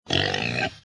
Pig
pig-squeak-47166.mp3